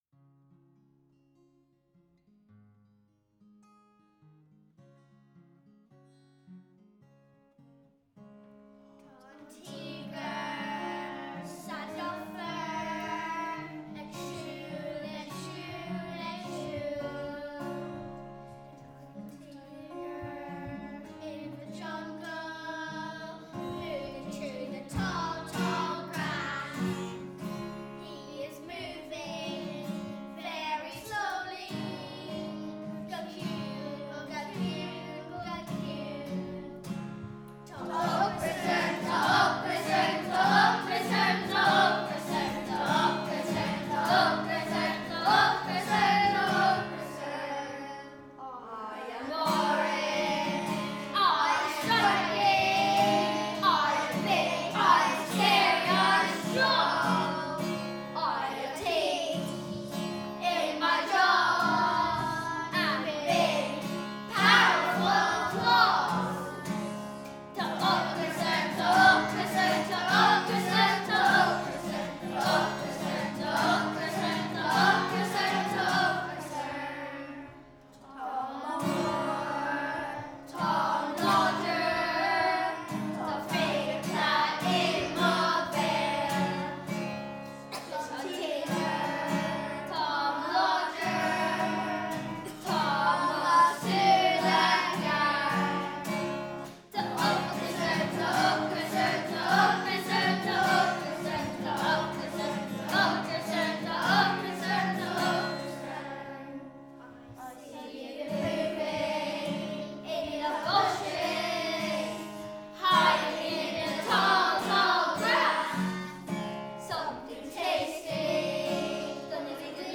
Recording Day at Ballina Arts Centre